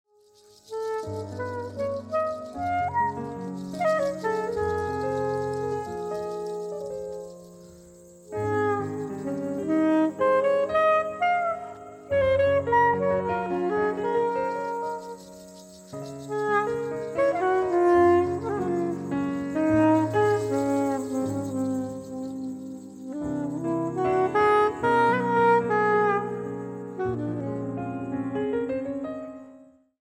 saxophone, bassclarinet
piano
guitar
drums, percussion